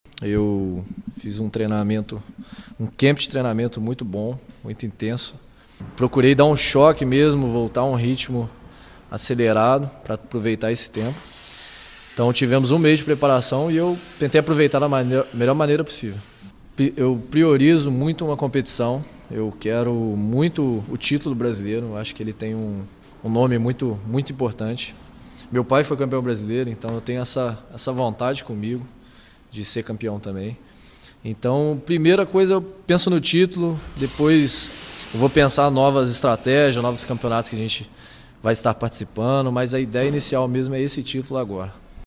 Durante a semana, ele falou com nossa reportagem sobre a expectativa para esta tarde.